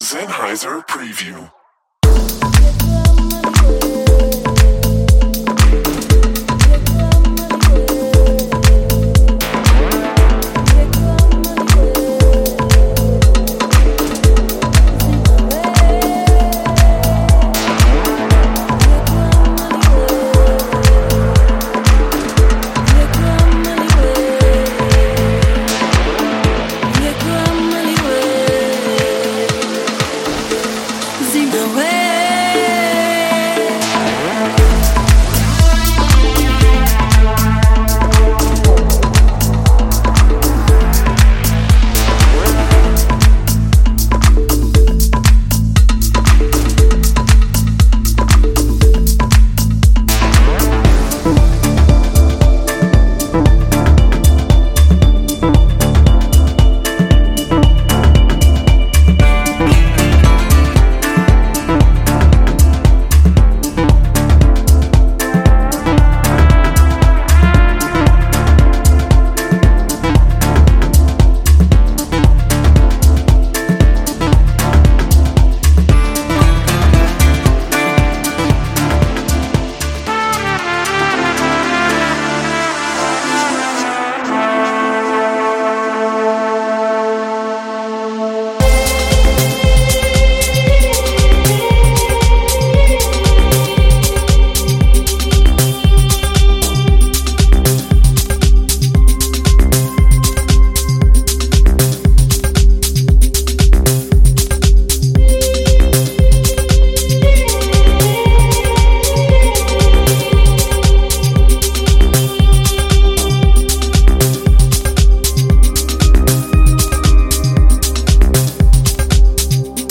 这个有机房，东方房，非洲房，深房和科技房的大熔炉具有119个庞大的词根集合，可解构10首绝对原创
kalimba，berimbau，kalimba，midi等)-129
中音-10节拍-115bpm – 122bpm